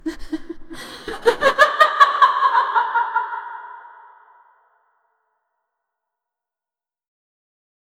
female-ghost-scary-laugh--pli2trnw.wav